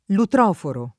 lutroforo [ lutr 0 foro ] s. m. (archeol.)